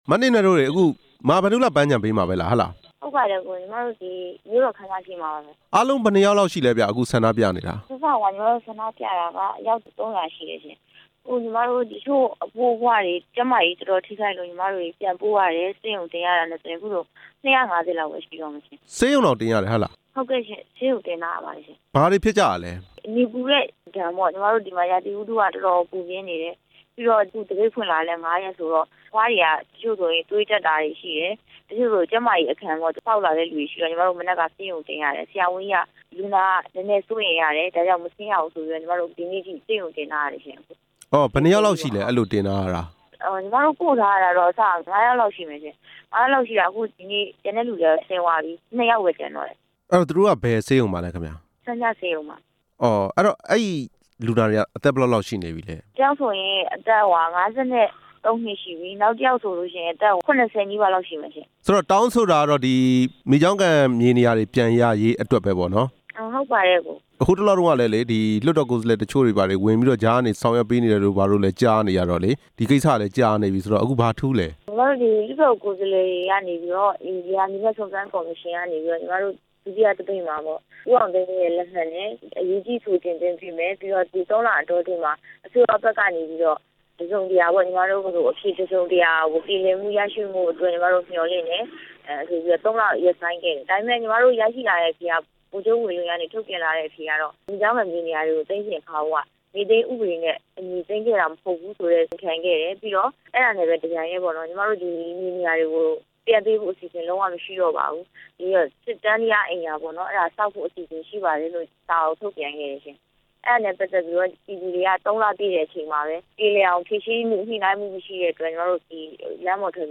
မိကျောင်းကန် သပိတ်စခန်းအကြောင်း မေးမြန်းချက်